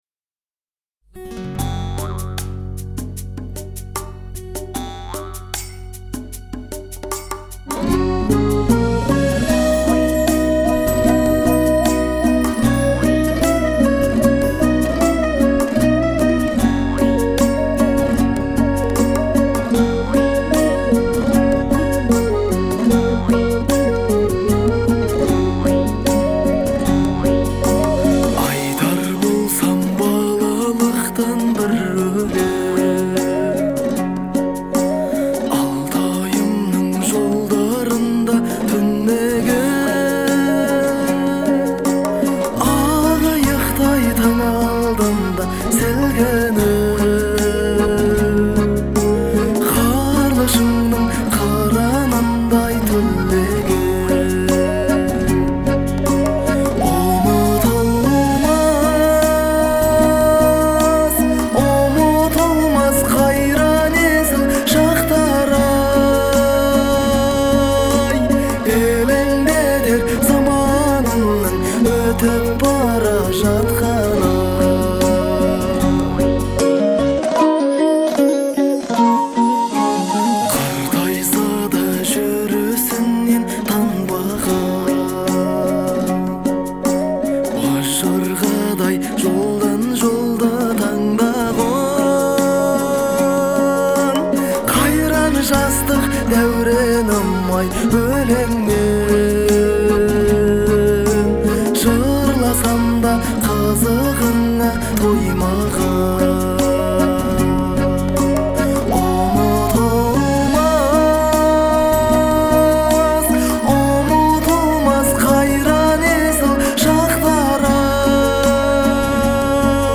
это трогательная песня в жанре казахской народной музыки
Звучание песни отличается мелодичностью и эмоциональностью